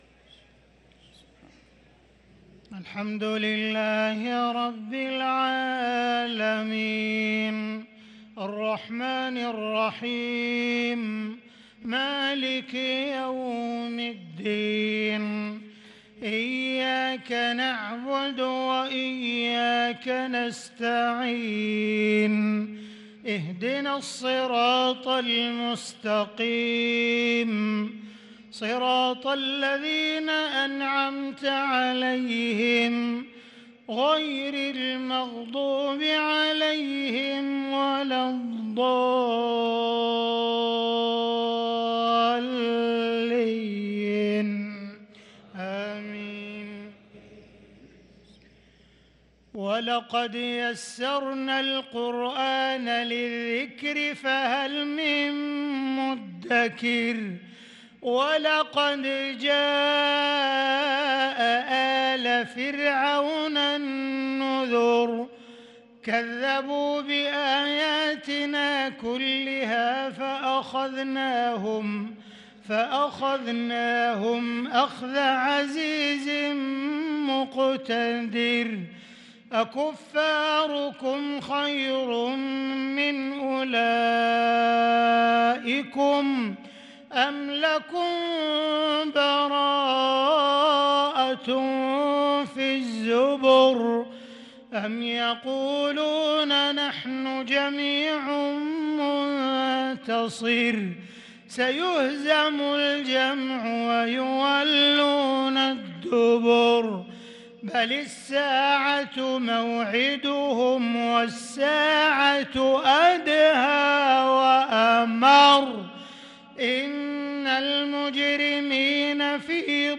صلاة المغرب للقارئ عبدالرحمن السديس 3 رمضان 1443 هـ
تِلَاوَات الْحَرَمَيْن .